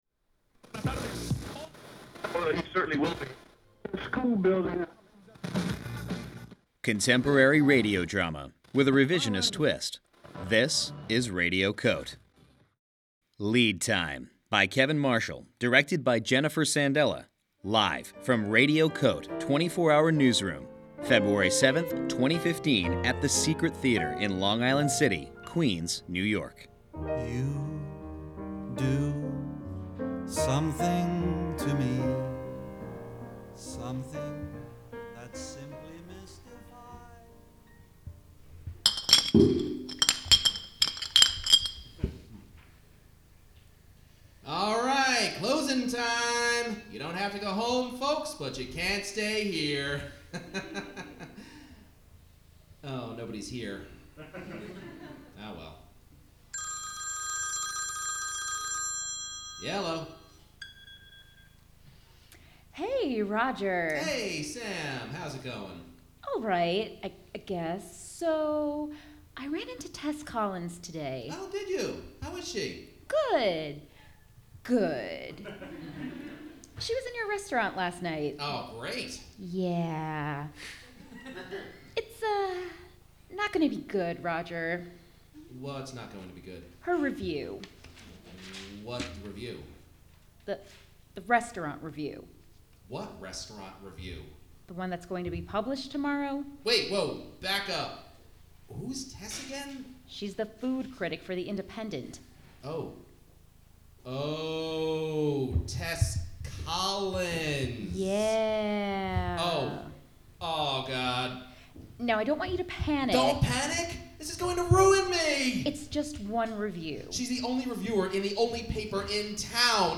Performed and recorded live as part of Radio COTE: 24-hour Newsroom, February 7, 2015 at The Secret Theater, Long Island City, Queens